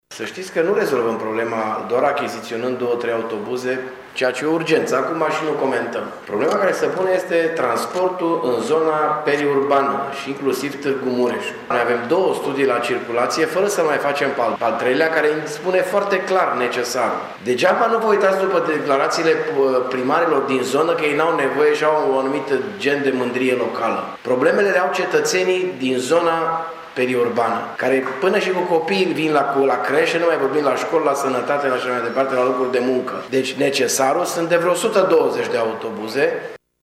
În replică, primarul Dorin Florea a spus că există două astfel de studii şi că ele arată clar nevoile municipiului şi a zonei periurbane: